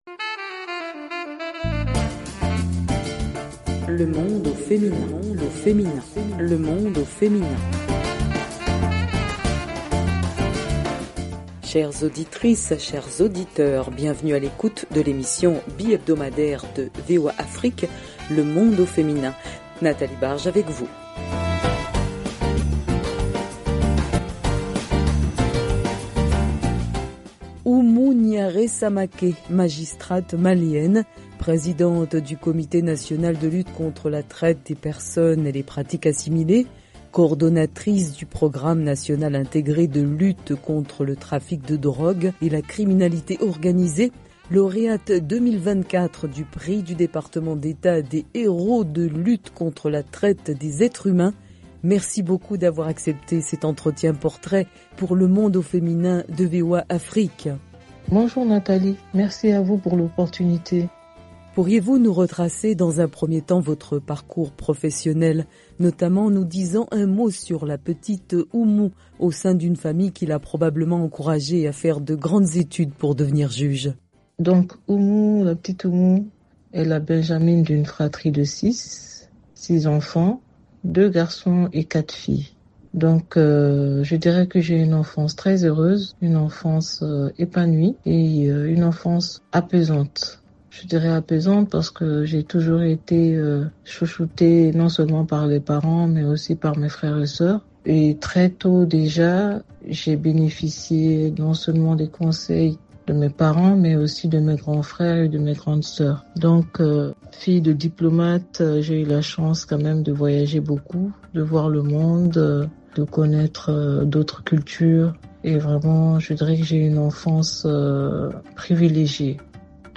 Le Monde au Feminin est une emission de la VOA Afrique qui donne la parole aux femmes africaines pour parler de leur droits et de leur leadership.